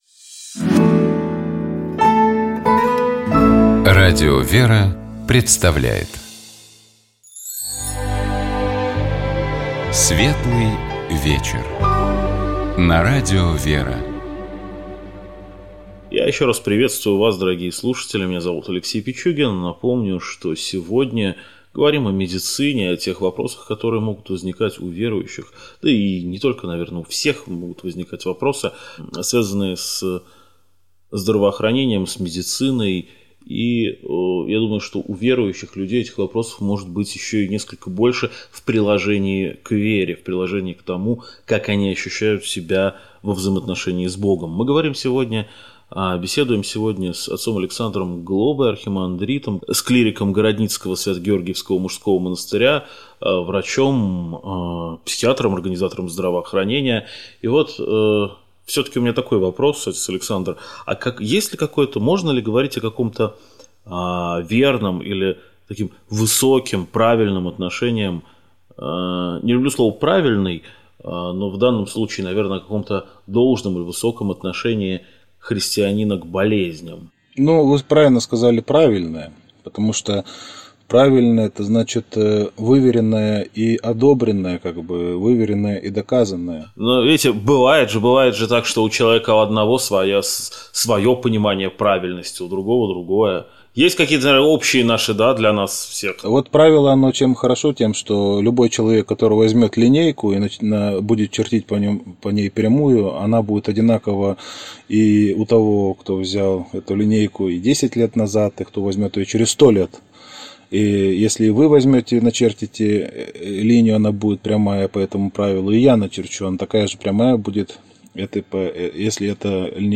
Часть 2 Скачать Поделиться В нашей студии был врач, психолог, организатор здравоохранения